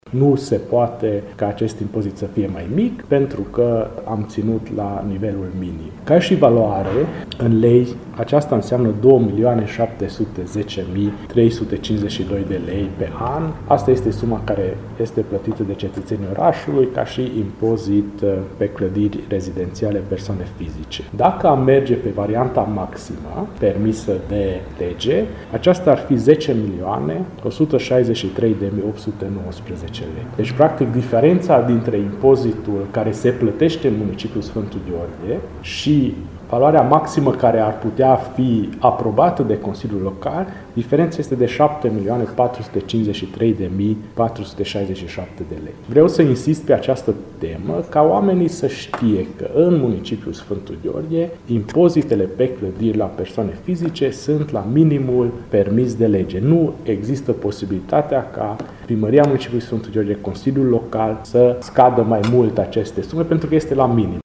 Primarul municipiului, Antal Arpad a declarat că în Sfântu Gheorghe există „percepţia” că impozitele sunt prea mari, însă acestea nu pot fi coborâte sub nivelul actual: